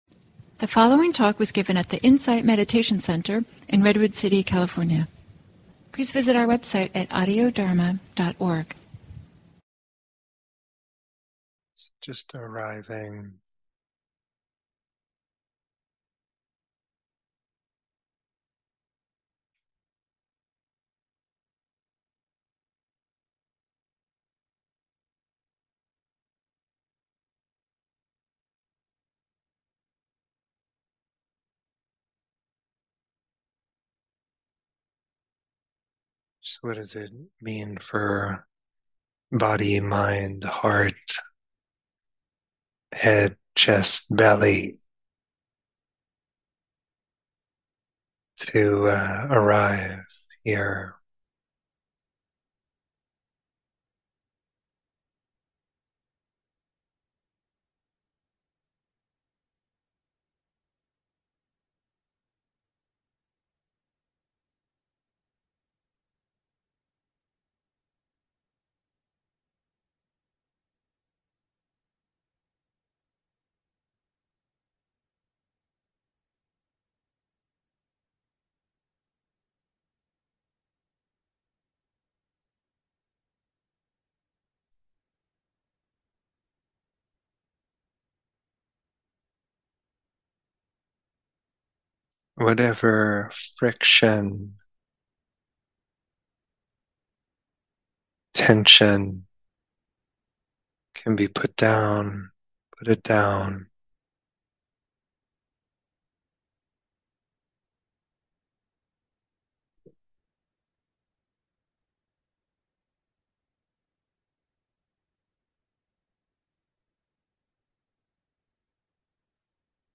Guided Meditation: Dedicating our practice